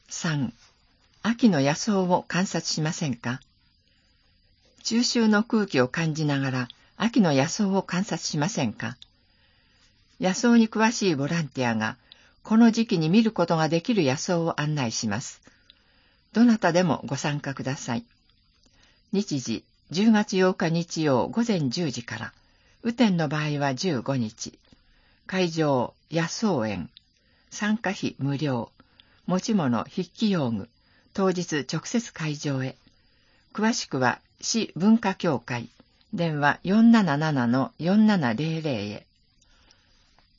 声の広報（平成29年9月15日号）